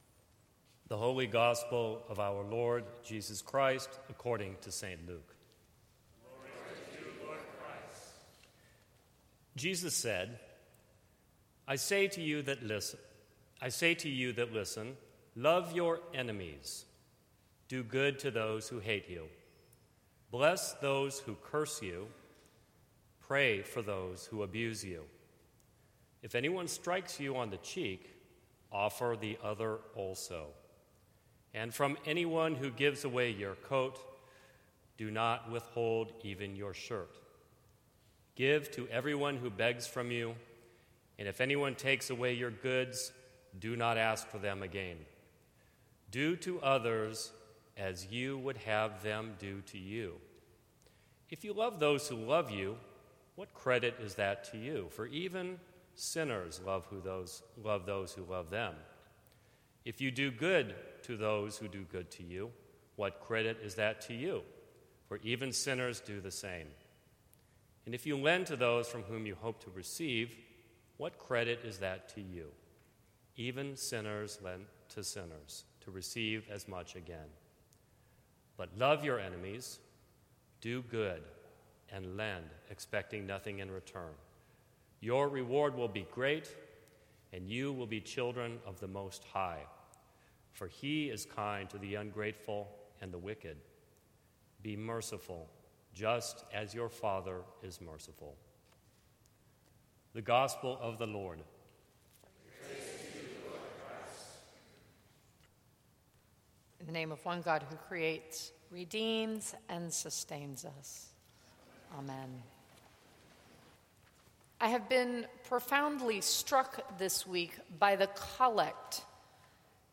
Sermons from St. Cross Episcopal Church What story do you want to tell?